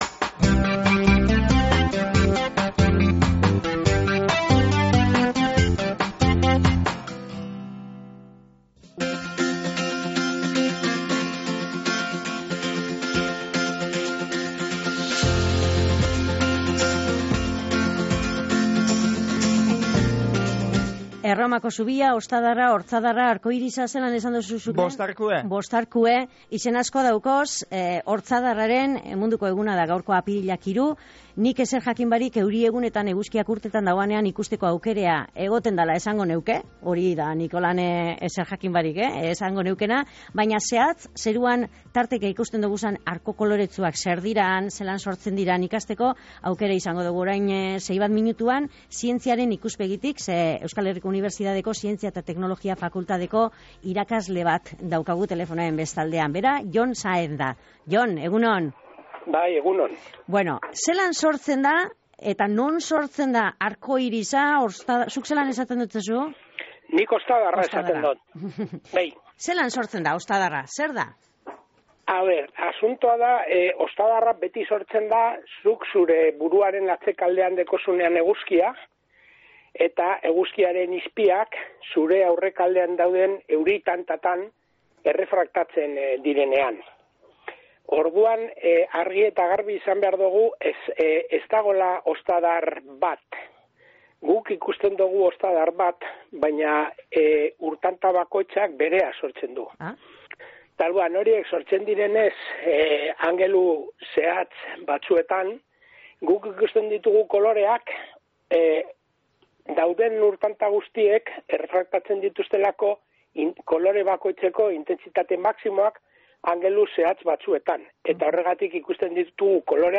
jardun dogu berbetan Goizeko Izarretan.